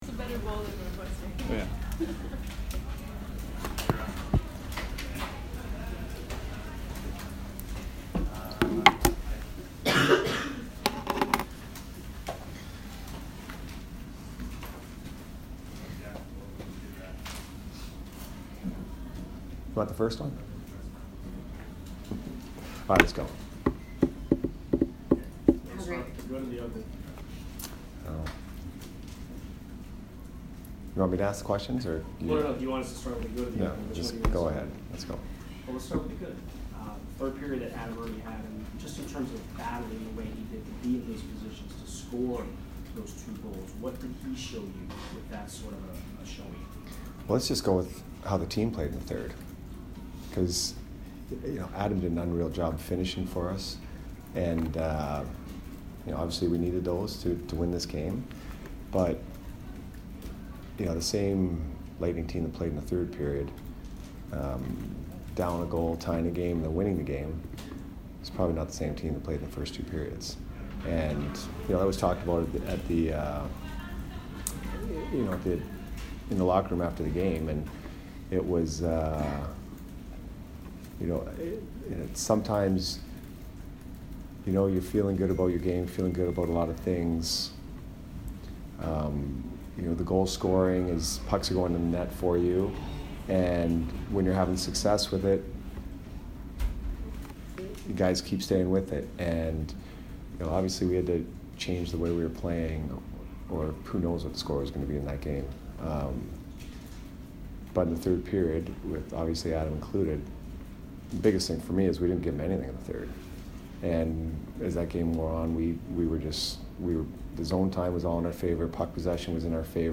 Jon Cooper post-game 12/29